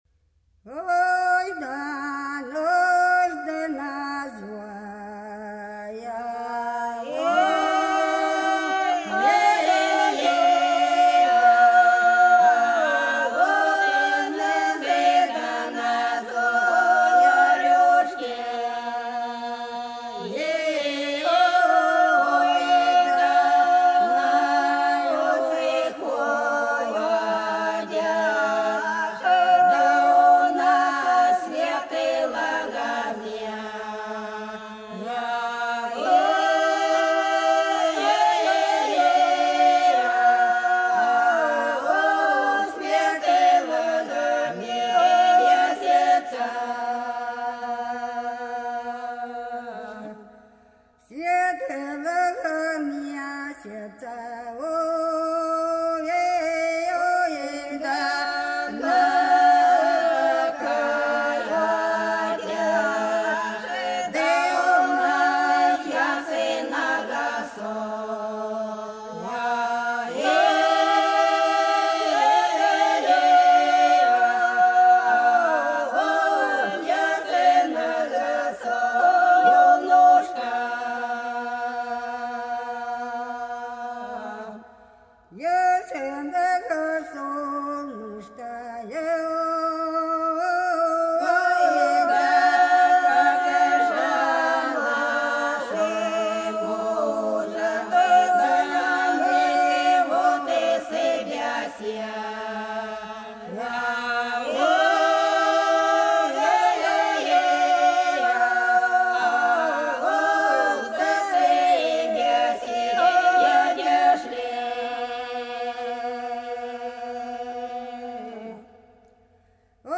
Долина была широкая (Поют народные исполнители села Нижняя Покровка Белгородской области) Ох, у нас на заре - протяжная